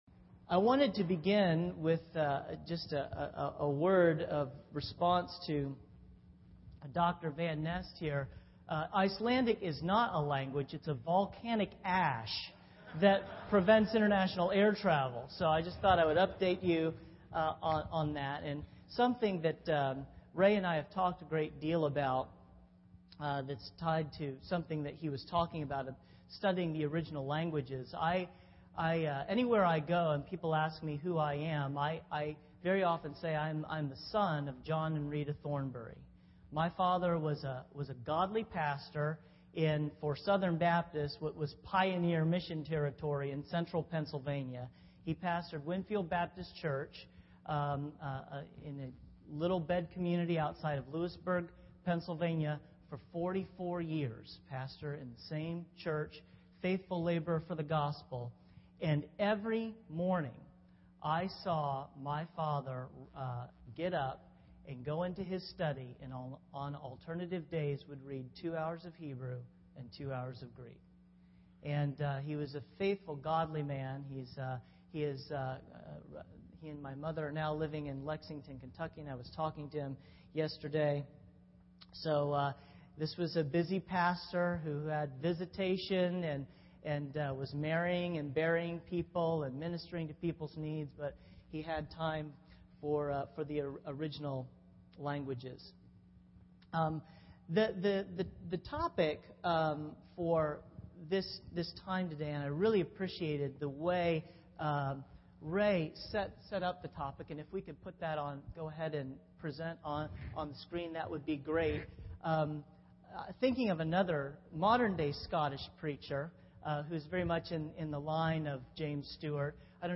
Address: The Bible Is Strange When You Are A Stranger